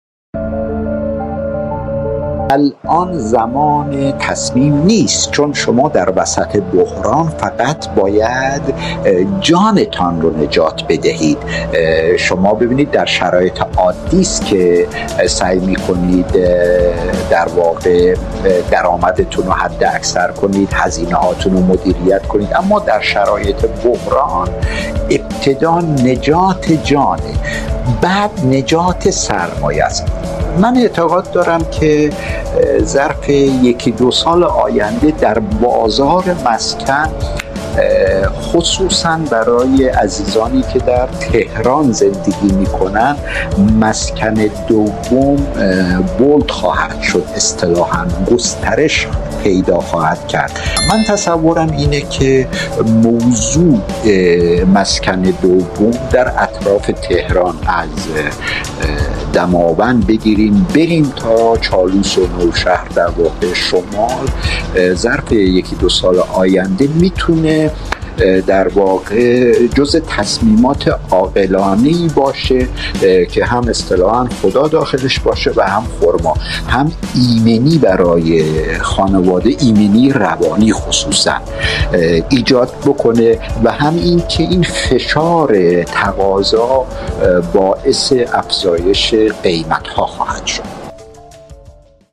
🎙 بخشی از گفت‌وگوهای کارشناسان درباره‌ی وضعیت پس از جنگ ایران و اسرائیل
🔊 ضبط‌شده از روم کلاب‌هاوس